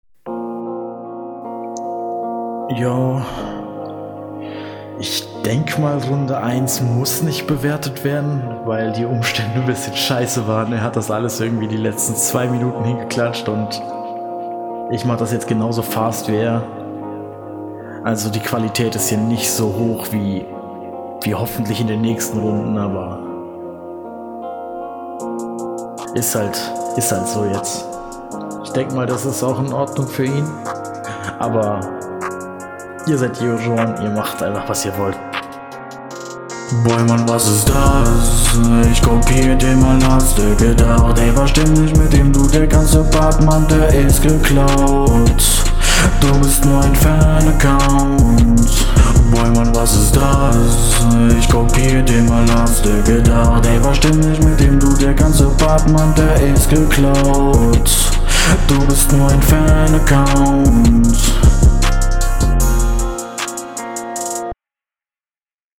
Das Autotune klingt ganz cool.
Intro unnötig.